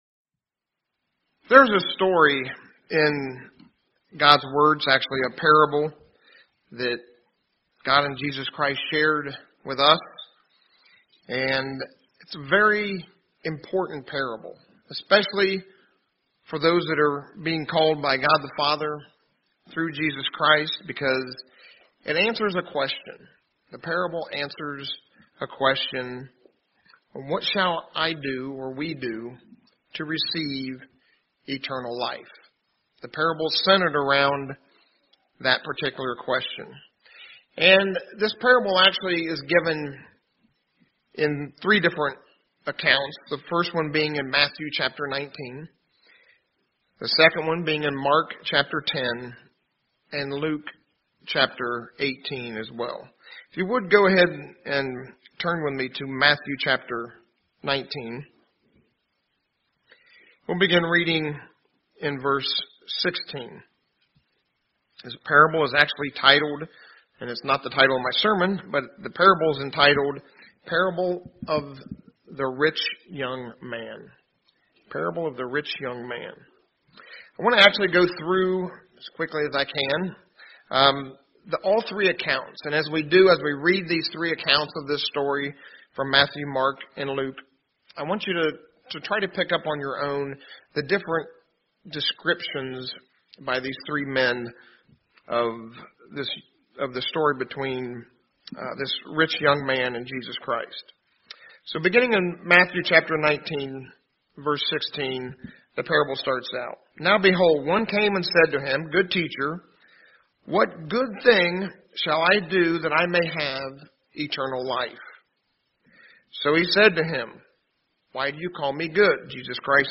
Sermon
Given in Indianapolis, IN